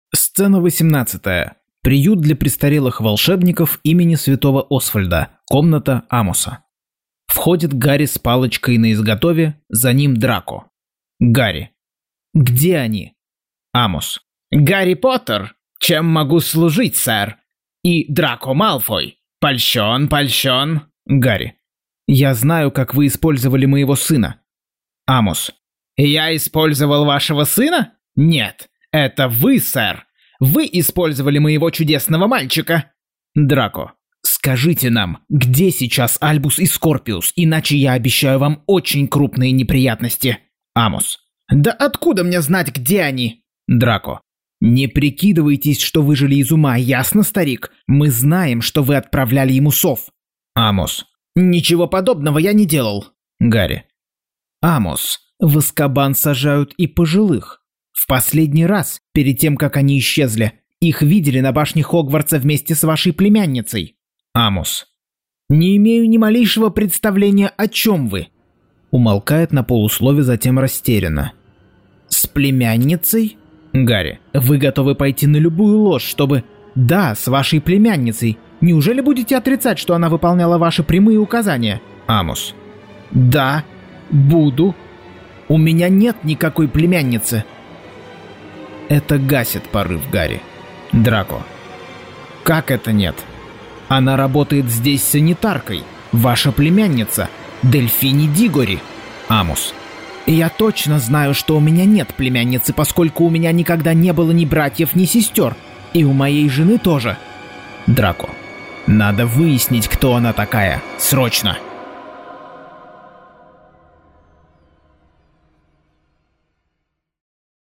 Аудиокнига Гарри Поттер и проклятое дитя. Часть 50.